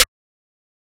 {Snare} Mafia 2.wav